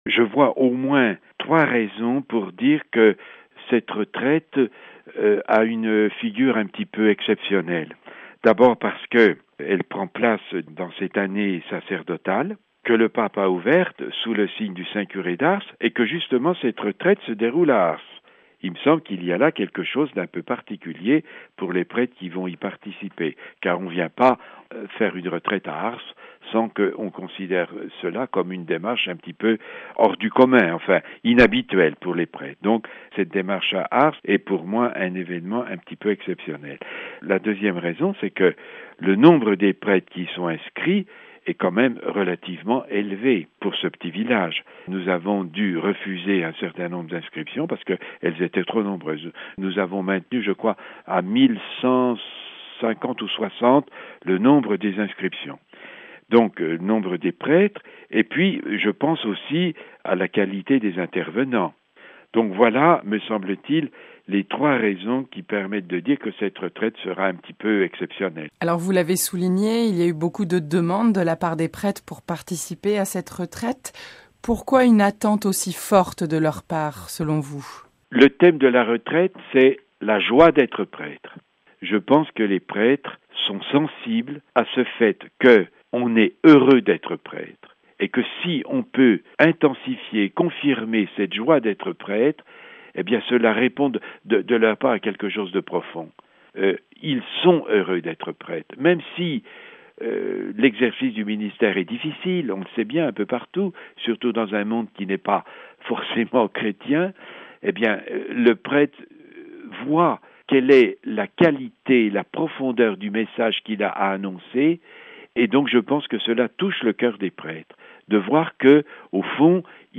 Monseigneur Guy Bagnard, évêque de Belley-Ars interrogé